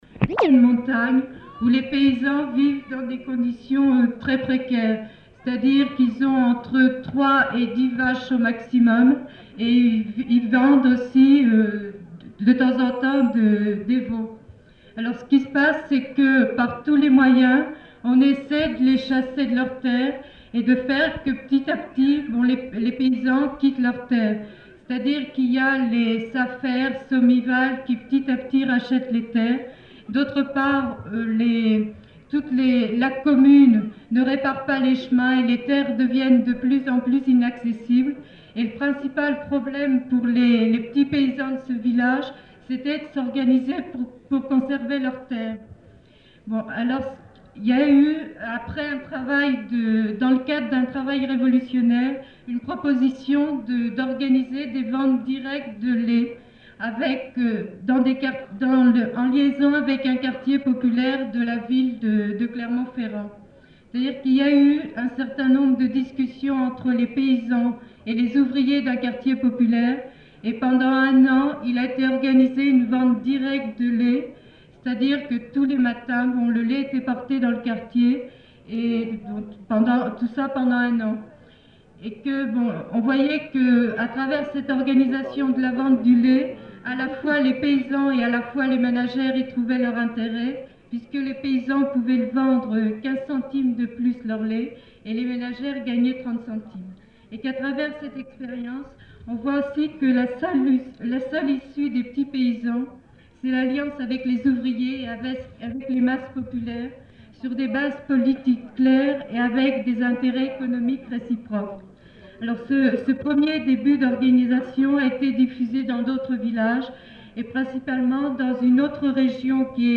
Lieu : [sans lieu] ; Aveyron
Genre : parole